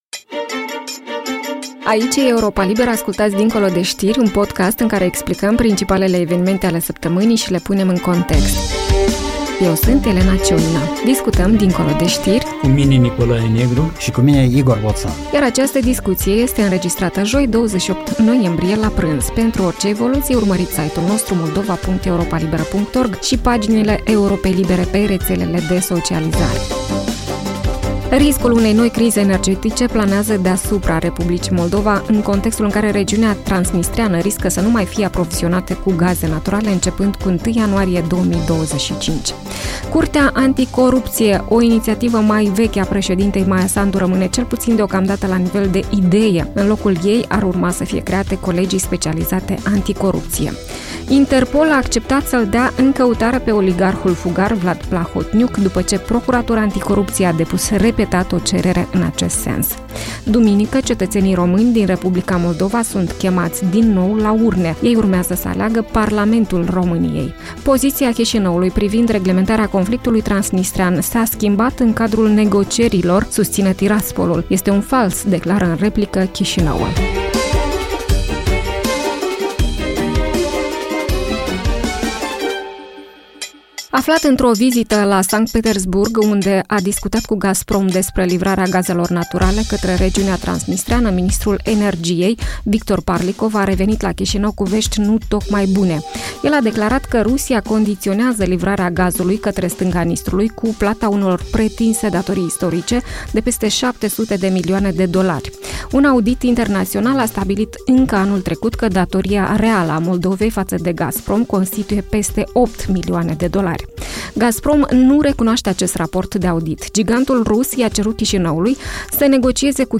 analiștii politici